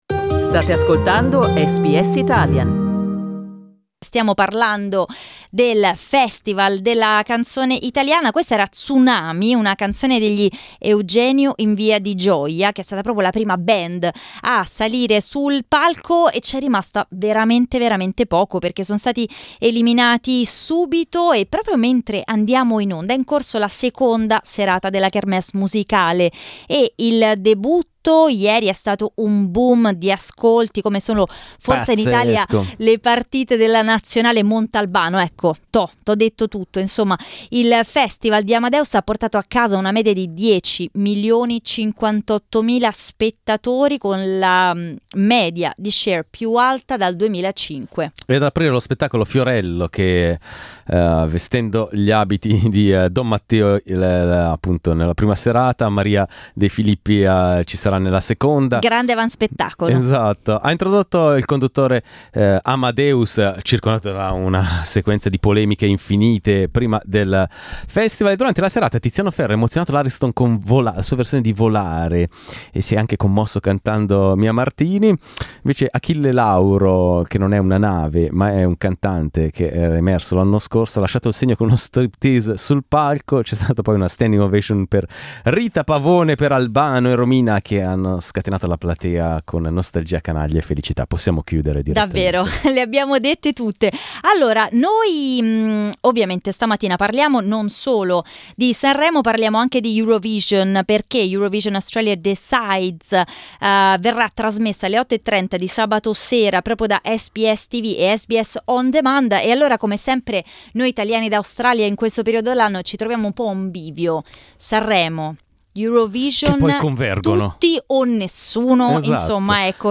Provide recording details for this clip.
In the Ligurian town the Festival of festivals has started and today we talked about it live on air with our listeners.